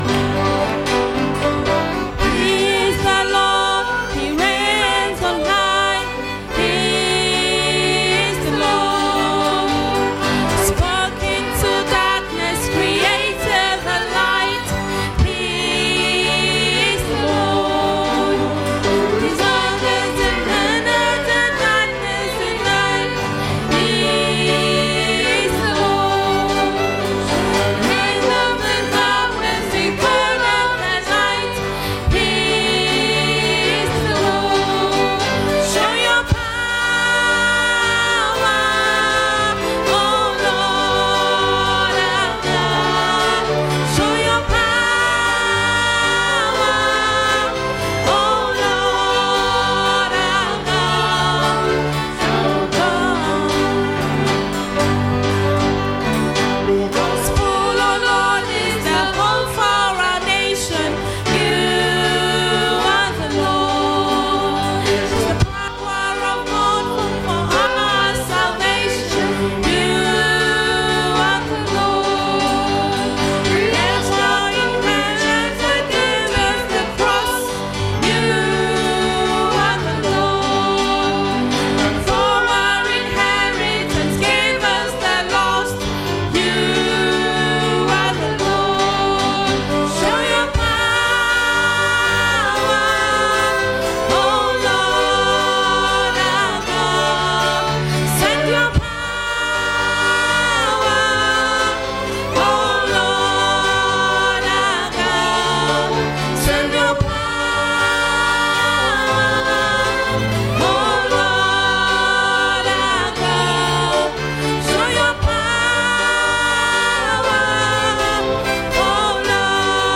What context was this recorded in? Our morning service this week includes the singing of carols and communion.